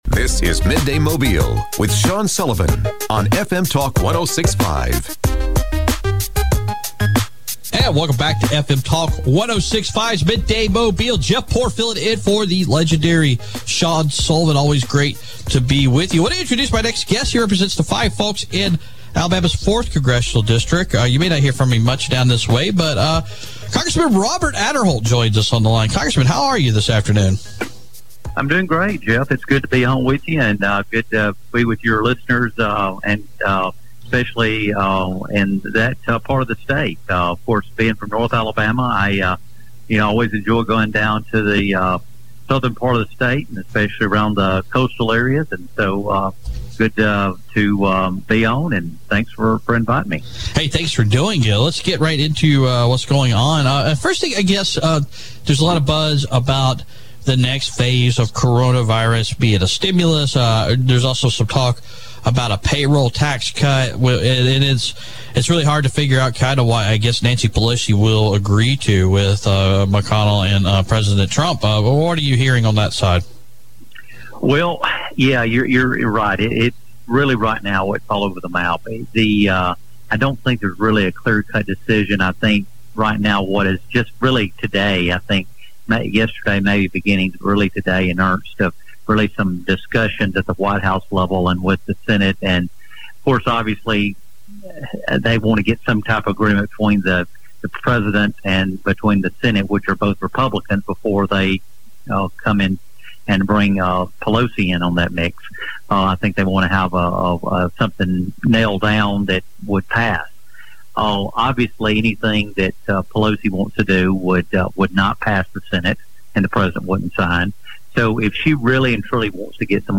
talks with US Representative Robert Aderholt about the latest discussions for a new Coronavirus Stimulus Bill.